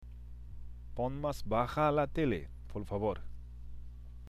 （ポン　マス　バハ　ラ　テレ　ポルファボール）